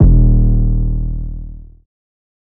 808 (Dreams - ROLI 2).wav